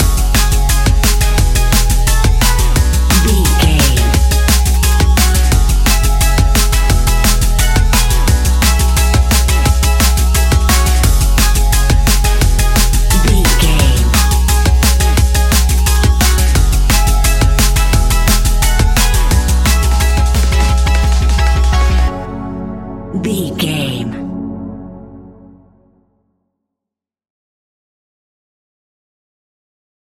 Ionian/Major
F♯
electronic
techno
trance
synths
synthwave
instrumentals